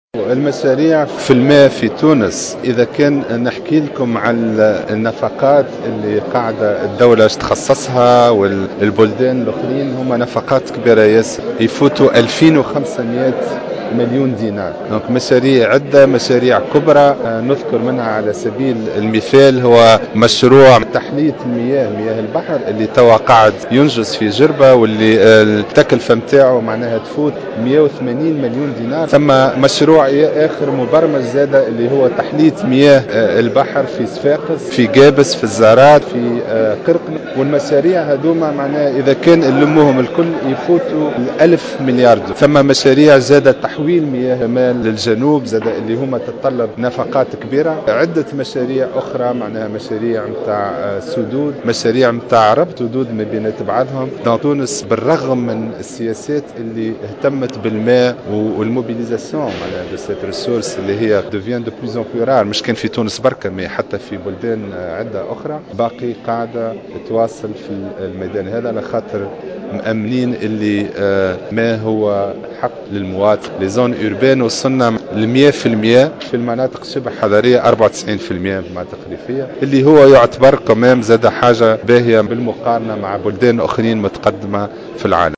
أكد وزير الفلاحة لسعد لأشعل على هامش ندوة دولية في مجال مياه الشرب والتطهير افتتحت بالعاصمة اليوم الثلاثاء 4 نوفمبر 2014 أن تونس تخصص نفقات هامة للإستثمار في مجال المياه على غرار مشروع تحلية المياه الذي بصدد الإنجاز حاليا بجربة وتفوق كلفته 18 مليون دينار اضافة الى مشاريع تحلية مياه بكل من قرقنة والزارات وقابس تصل كلفتها الإجمالية ألف مليار .